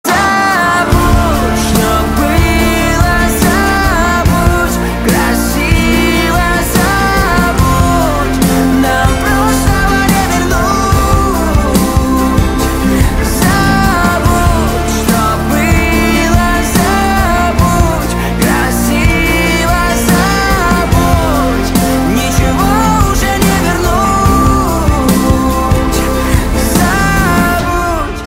поп
грустные